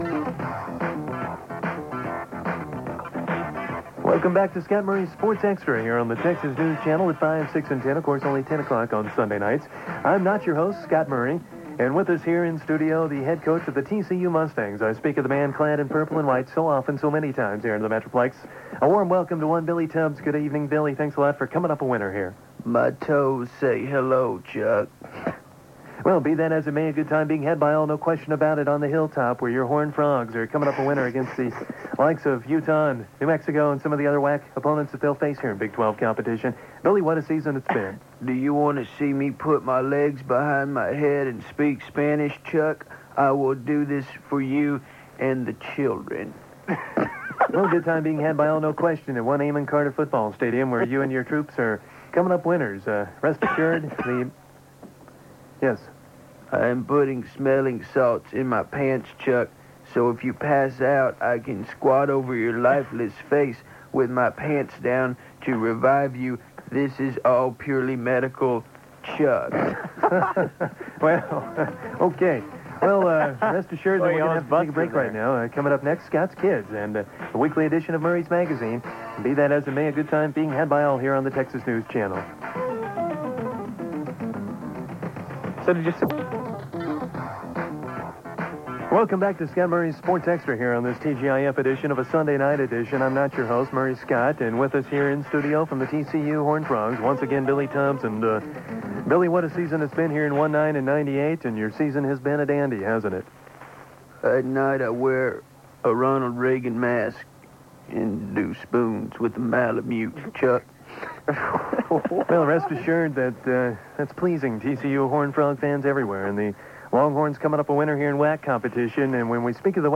Then about a minute of the Rant, where they talk about it.